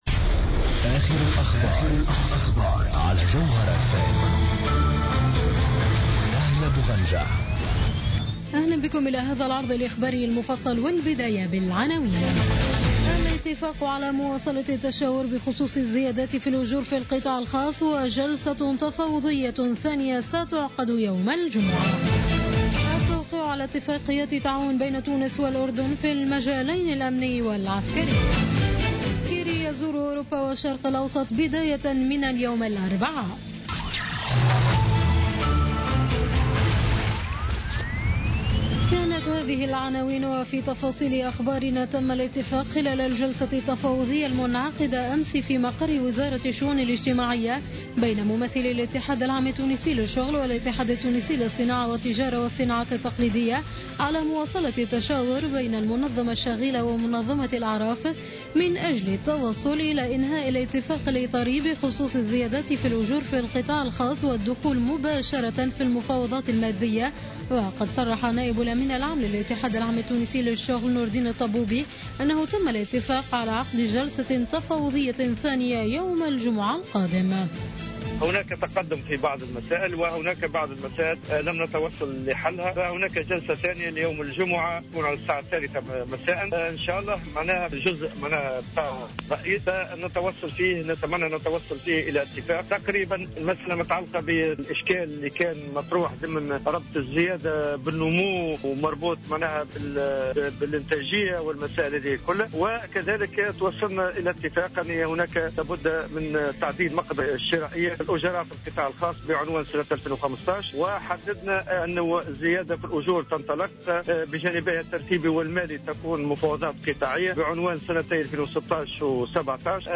نشرة أخبار منتصف الليل ليوم الاربعاء 21 أكتوبر 2015